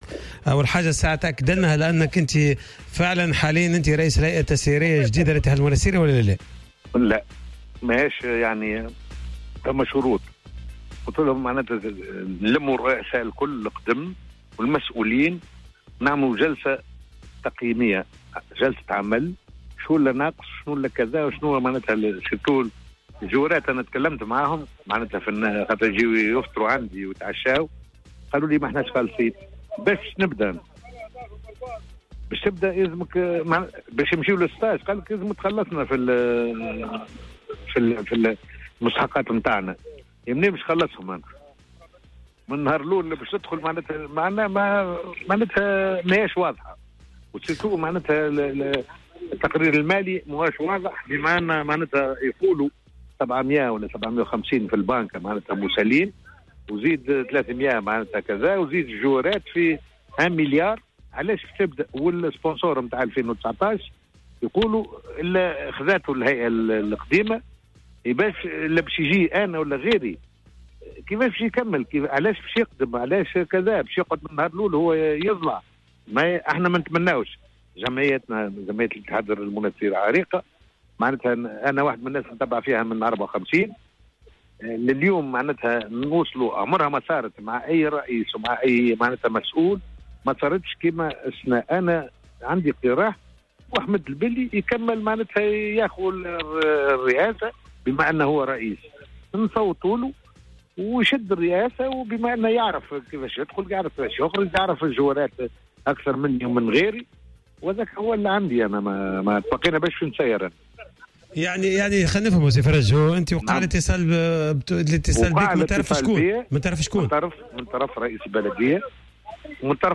في مداخلة في حصة "راديو سبور"